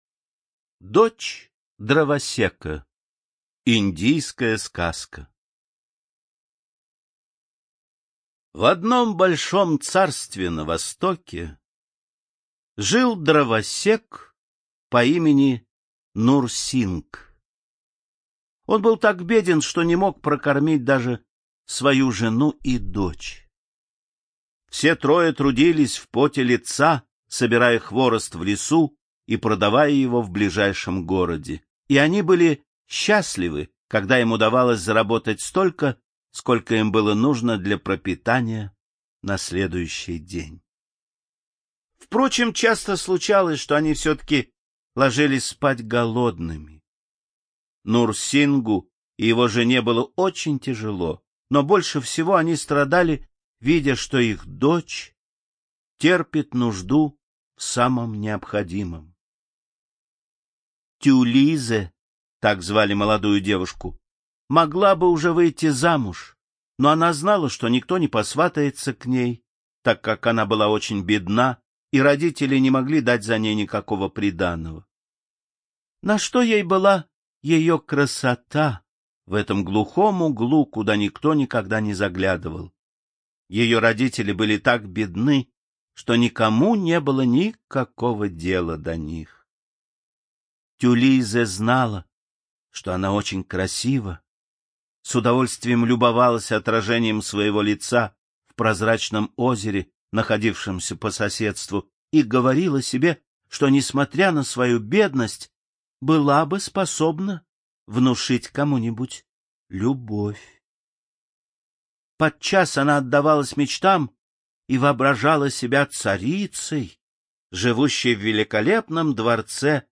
ЖанрСказки